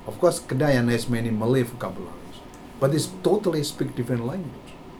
S1 = Taiwanese female S2 = Indonesian male Context: S2 is talking about the Kedayan language that is spoken in Brunei.
S2's voice fades away when saying the word, and there is little stress on the second syllable.